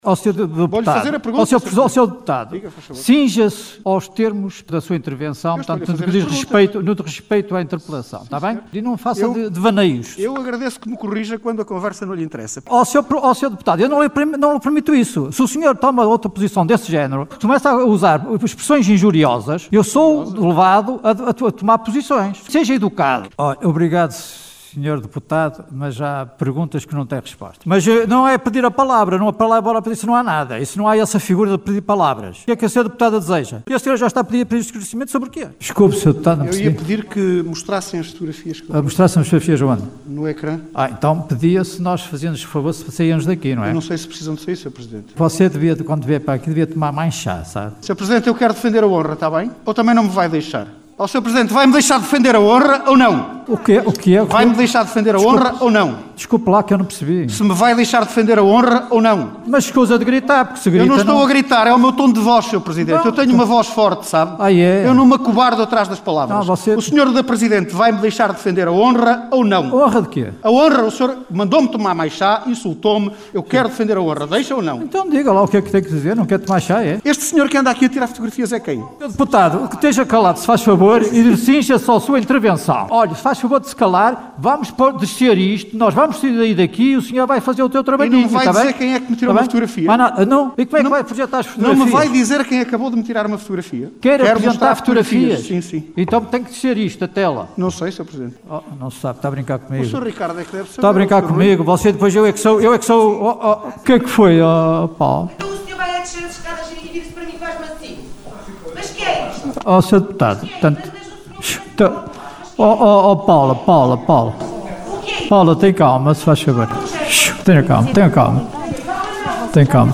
Realizada no cineteatro dos bombeiros voluntários de Vila Praia de Âncora, decorreu em tom de crispação de início ao fim.